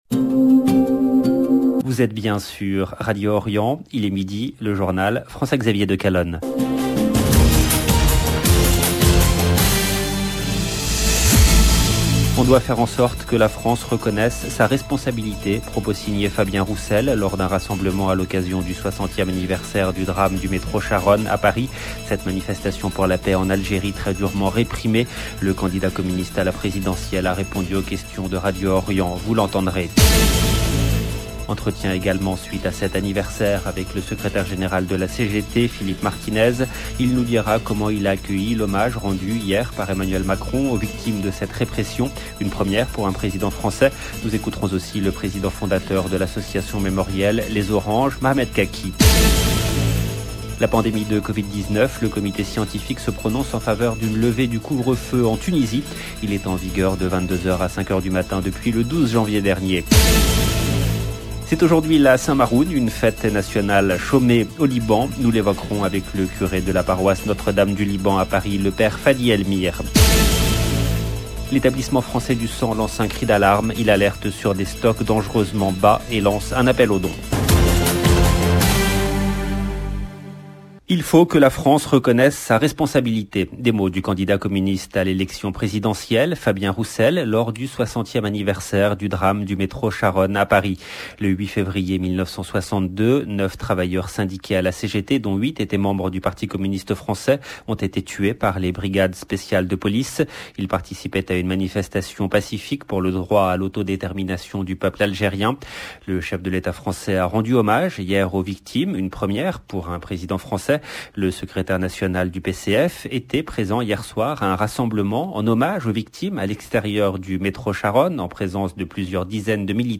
Le candidat communiste à la présidentielle a répondu aux questions de Radio Orient, vous l’entendrez. Entretien également suite à cet anniversaire avec le secrétaire général de la CGT Philippe Martinez.